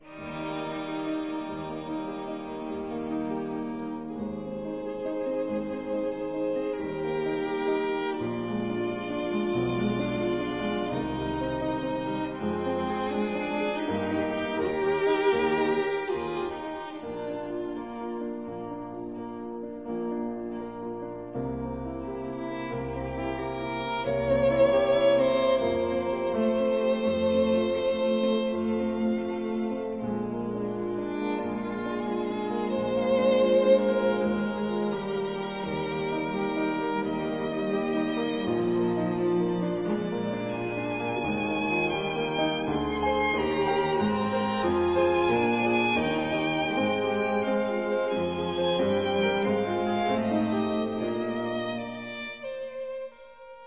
violin
violoncello
piano; rec. at Studio AMU Prague 1998